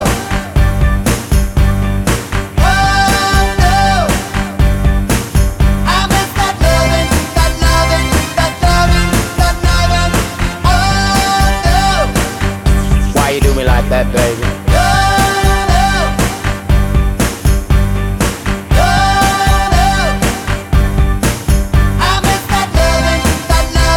no Backing Vocals R'n'B / Hip Hop 2:58 Buy £1.50